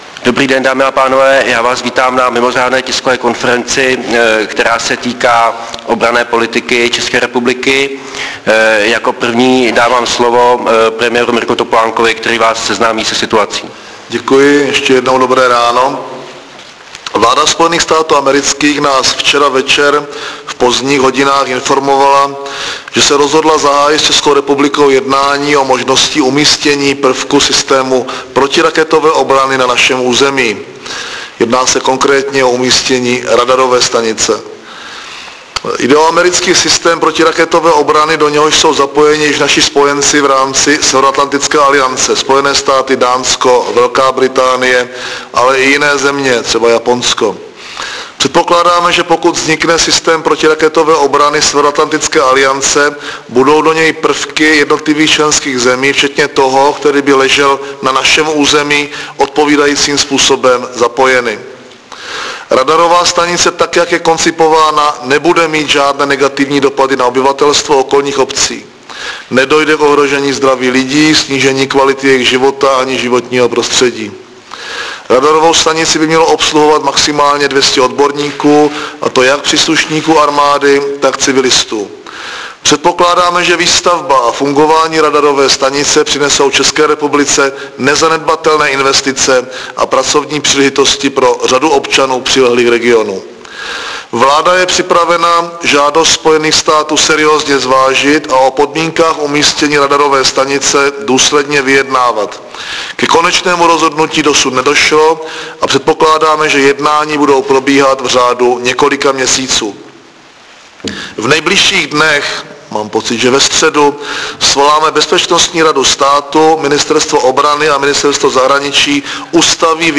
Mimořádná tisková konference předsedy vlády ČR Mirka Topolánka k obranné politice České republiky